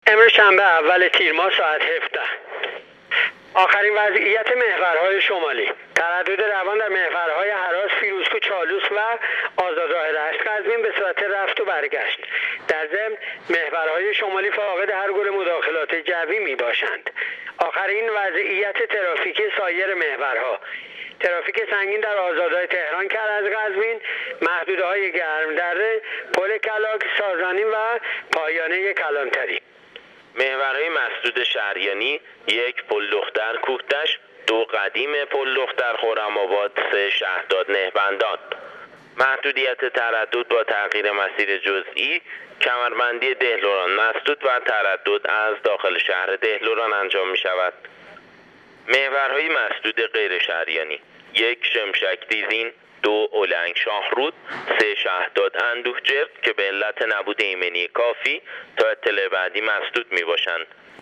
گزارش رادیو اینترنتی وزارت راه و شهرسازی از آخرین وضعیت‌ ترافیکی راه‌های کشور تا ساعت ۱۷ اول تیرماه/ تردد عادی و روان در تمامی محورهای شمالی کشور / ترافیک سنگین در آزادراه تهران-کرج-قزوین